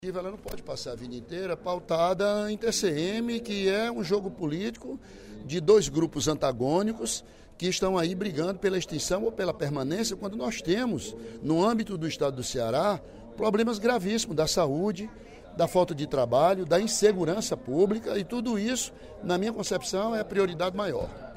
O deputado Ely Aguiar (PSDC) cobrou, durante o primeiro expediente da sessão plenária desta terça-feira (20/06), que a Assembleia Legislativa supere a discussão sobre a proposta de emenda constitucional (PEC) que prevê a extinção do Tribunal de Contas dos Municípios (TCM) e que passe a tratar de outros temas relevantes ao Estado.